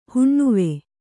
♪ huṇṇuve